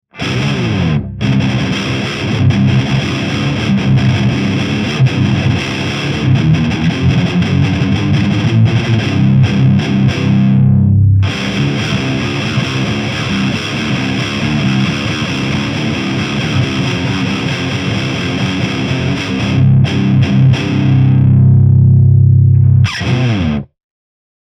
前回に続きご愛用のエフェクターのみでブーストしてみました。
GROOVE TUBE GT-ECC83Sは低音が出るのとコンプ感が特徴です。
ゲインアップはしますが、ハイが多少出なくなります。
MTRはZOOM MRS-8を使いました。マイクはSM57とPG57
GAIN7 Bass8 Middle8 Treble7
LEED2